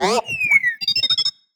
happy4.wav